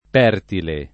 Pertile [ p $ rtile ]